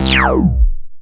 ULTRA ACID F.wav